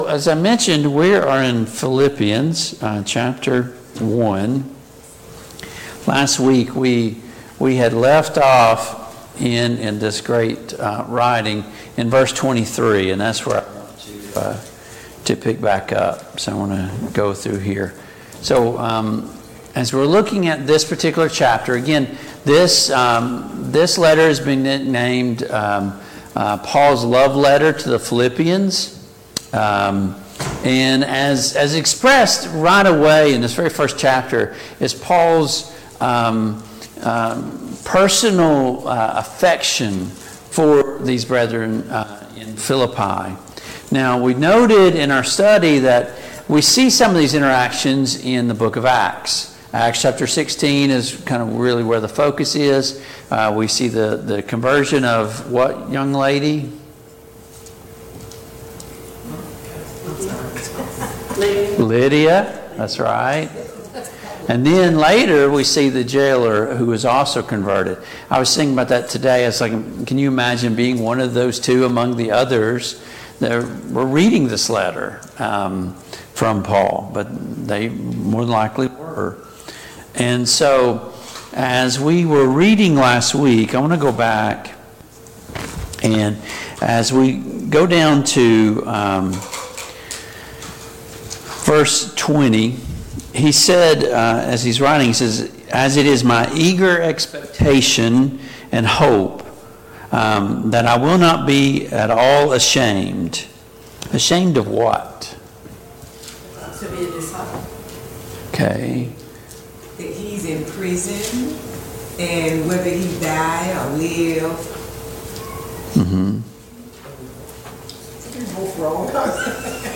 Passage: Philippians 1:21-27 Service Type: Mid-Week Bible Study